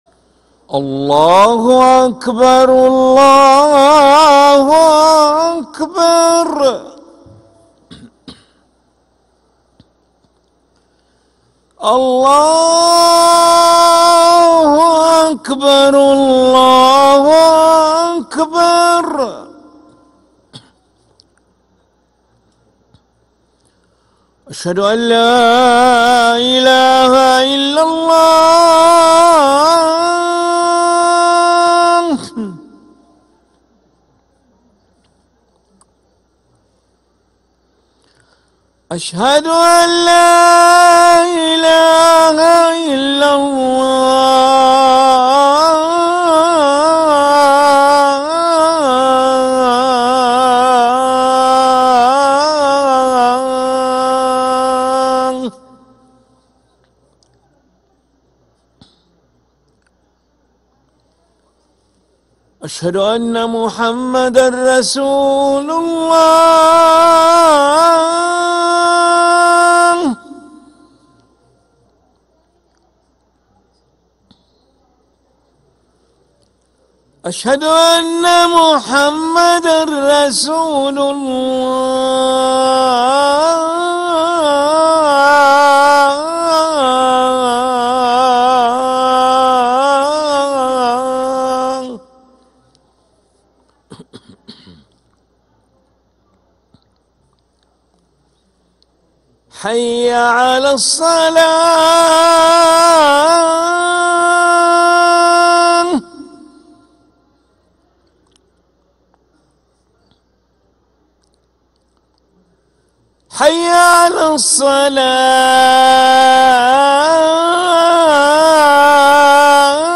أذان العشاء للمؤذن علي ملا الخميس 4 جمادى الآخرة 1446هـ > ١٤٤٦ 🕋 > ركن الأذان 🕋 > المزيد - تلاوات الحرمين